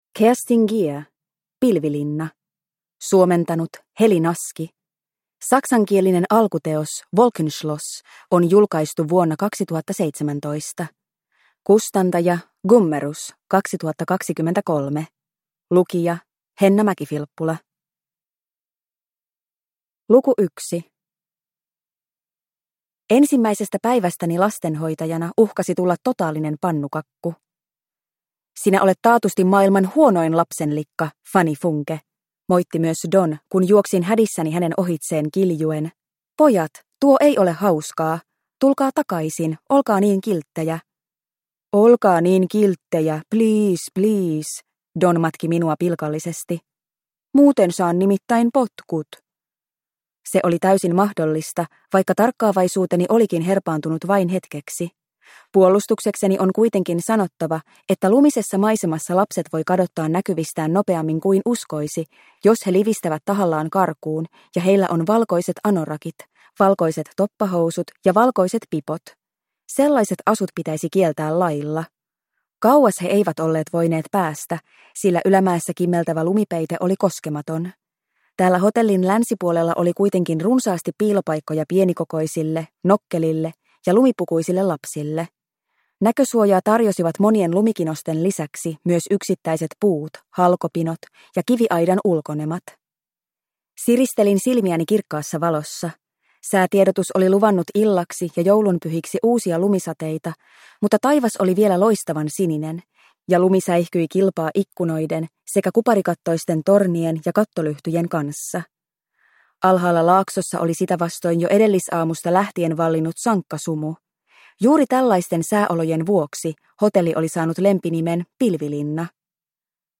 Pilvilinna – Ljudbok – Laddas ner